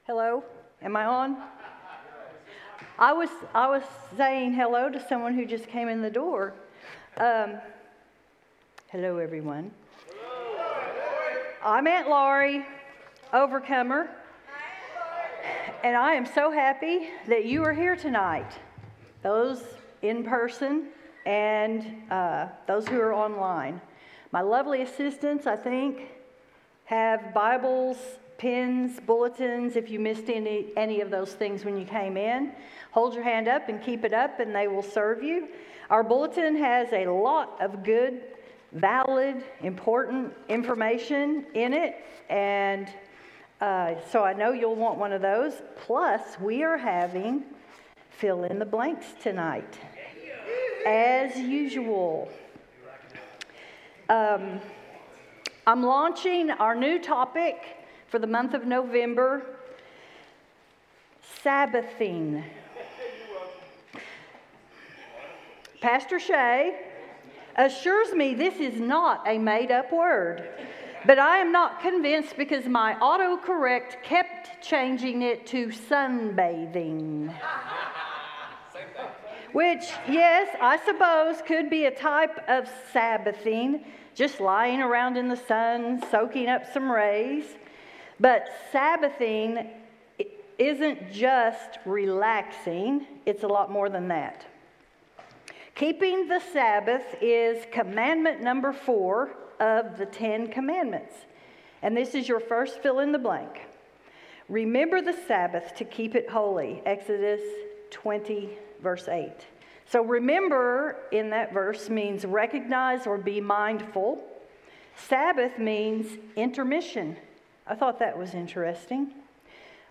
Sermons | Serenity Church